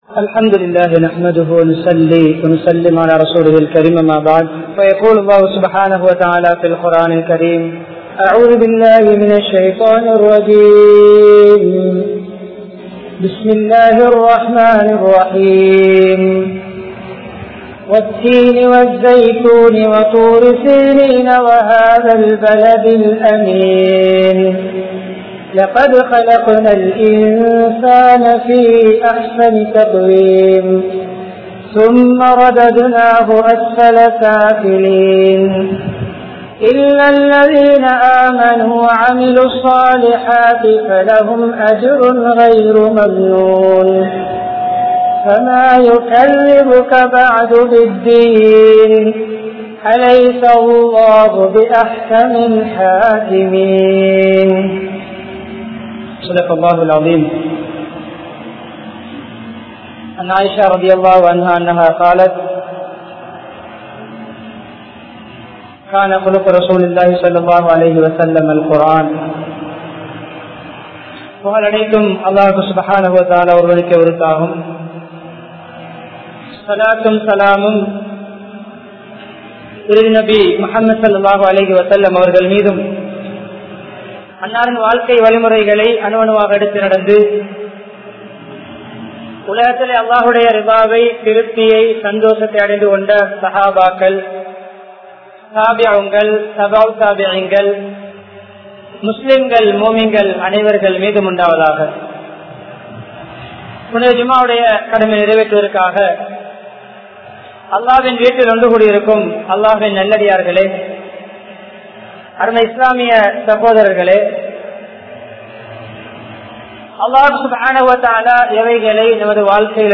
Entha Santharpathilum Ahlaqkai Ilakka Vendaam!(எந்த சந்தர்பத்திலும் அஹ்லாக்கை இழக்க வேண்டாம்!) | Audio Bayans | All Ceylon Muslim Youth Community | Addalaichenai
Peliyagoda Baithul Mukarram Jumua Masjidh